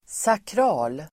Ladda ner uttalet
Folkets service: sakral sakral adjektiv, sacred Uttal: [sakr'a:l] Böjningar: sakralt, sakrala Synonymer: helig, kyrklig, religiös Definition: gudstjänst- Exempel: en sakral stämning (a sanctified atmosphere)